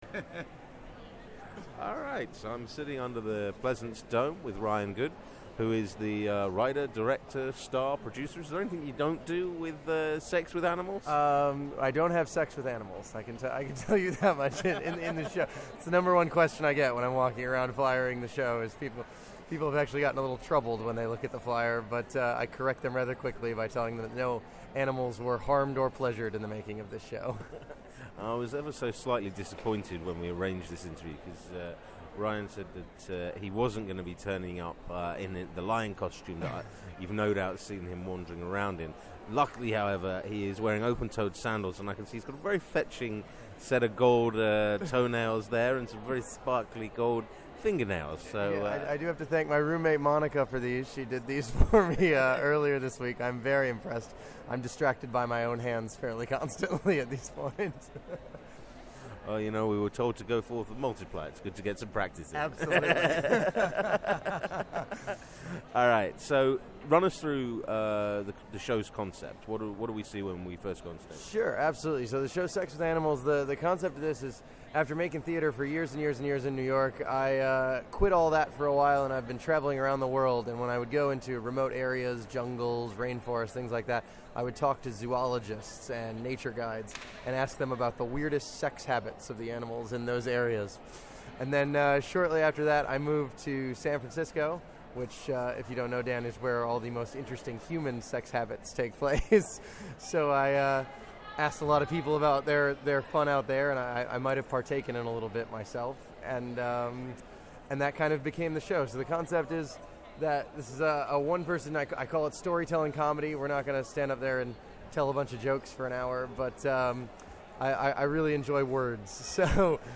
Edinburgh Audio 2013